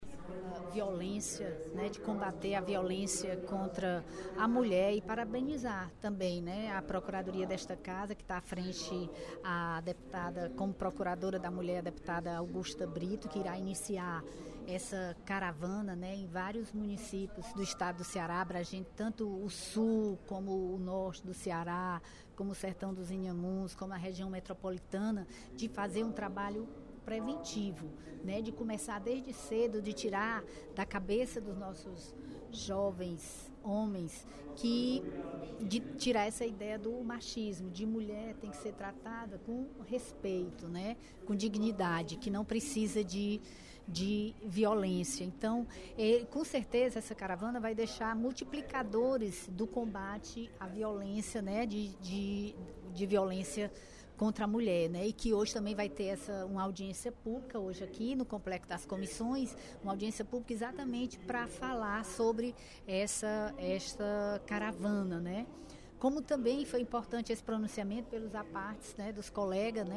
A deputada Bethrose (PMB) ressaltou, durante o primeiro expediente da sessão plenária desta quarta-feira (17/05), o lançamento da I Caravana de Combate à Violência Contra a Mulher nesta quarta, na Assembleia Legislativa.